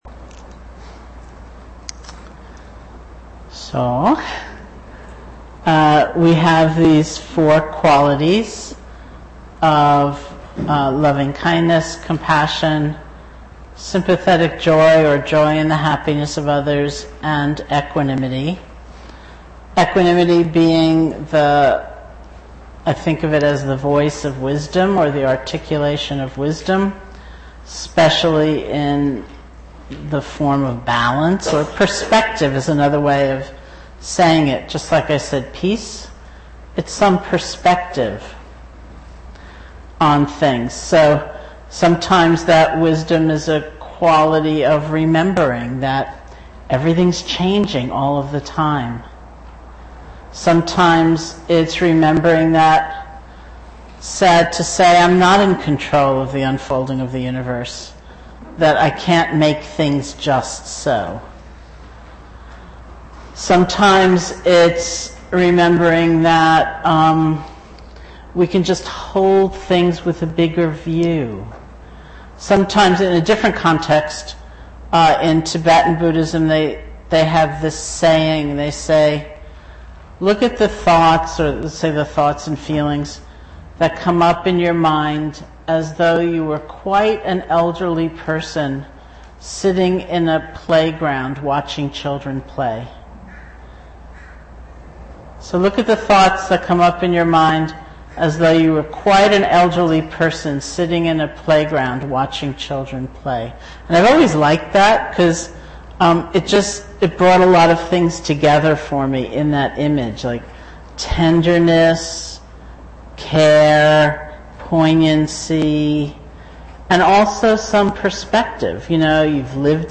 Teacher: Sharon Salzberg Date: 2010-07-25 Venue: Seattle Insight Meditation Center Series [display-posts] TalkID=830